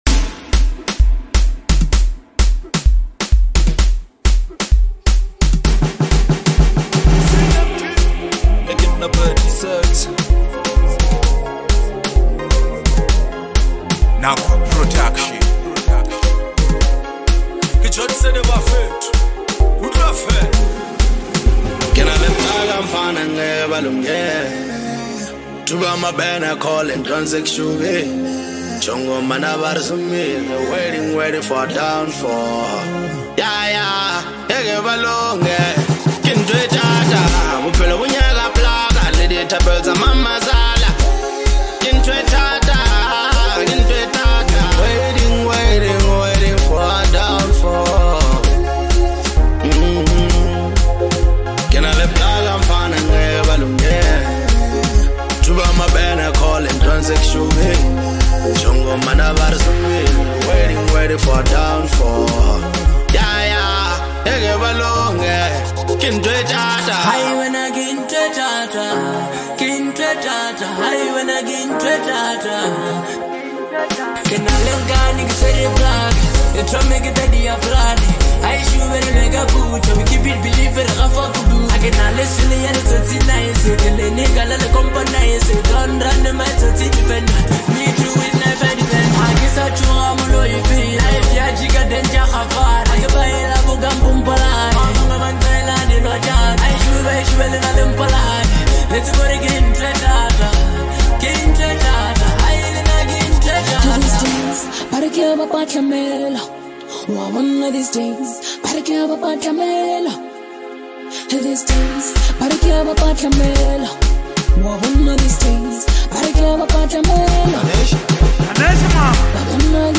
uplifting anthem